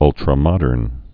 (ŭltrə-mŏdərn)